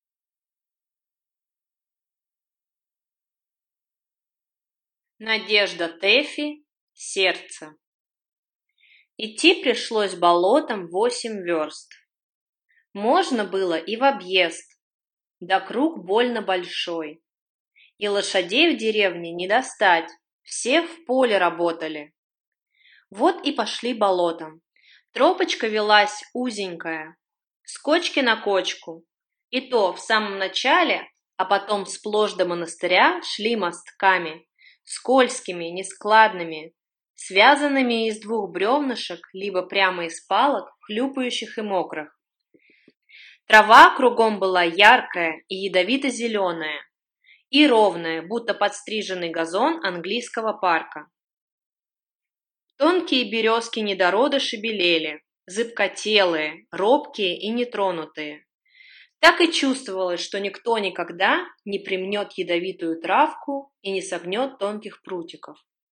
Аудиокнига Сердце | Библиотека аудиокниг
Прослушать и бесплатно скачать фрагмент аудиокниги